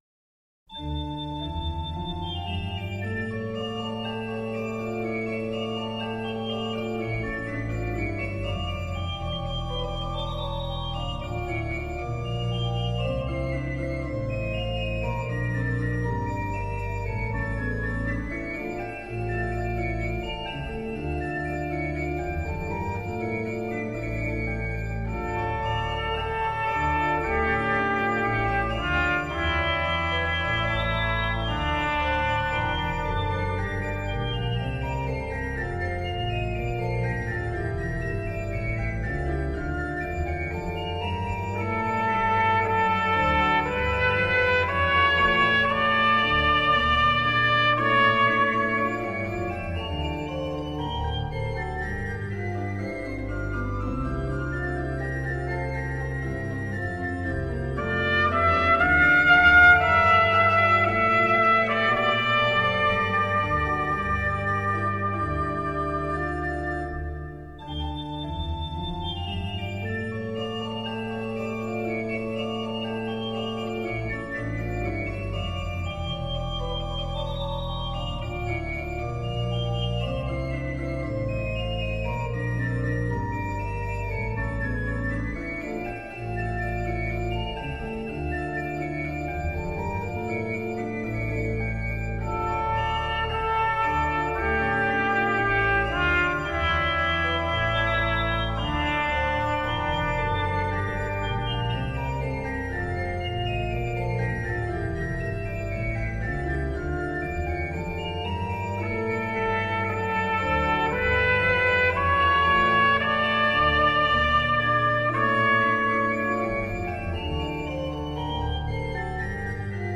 С органом хорошо, особенно начало, а потом пошире река представилась...
Да, про Баха, там где вторая тема духовых вступает, река представилась шире, но это это не столь существенно:)